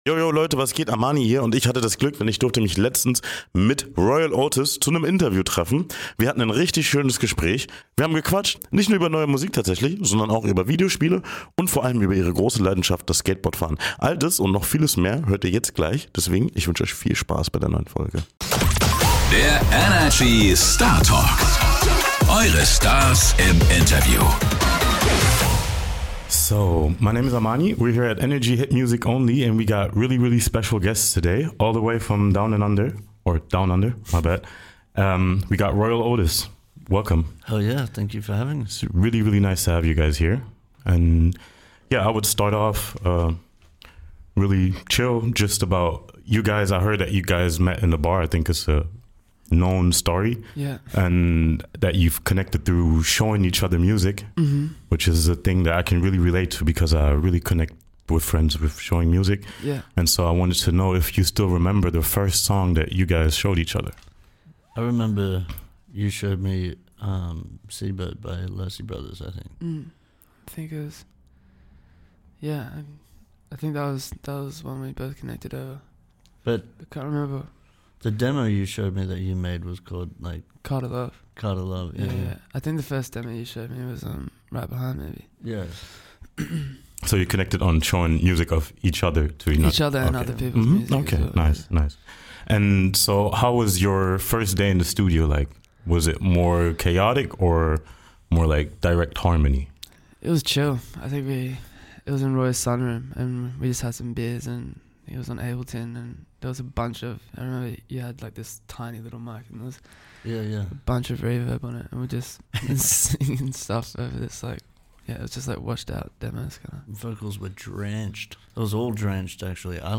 Dass das Duo aber nicht nur Musik im Kopf hat, zeigt unser Interview, in dem es plötzlich auch um Gaming-Sessions, und den einen oder anderen Herzschmerz geht.